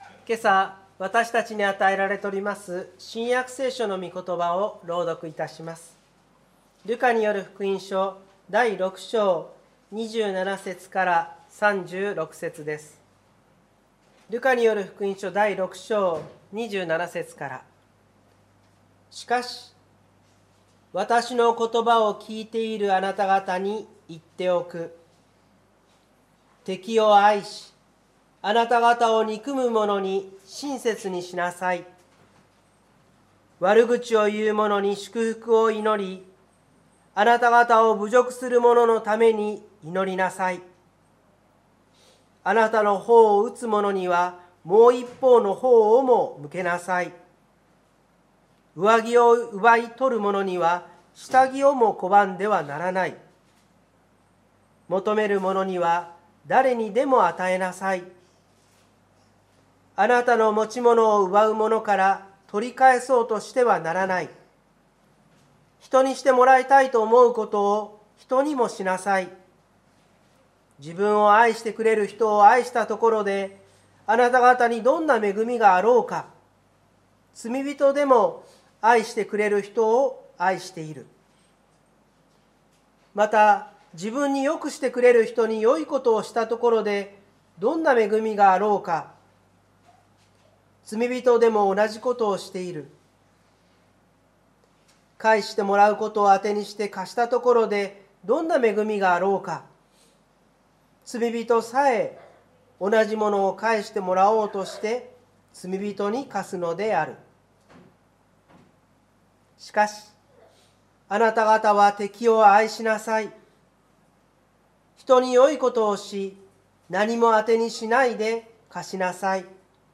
湖北台教会の礼拝説教アーカイブ。